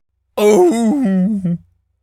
seal_walrus_death_03.wav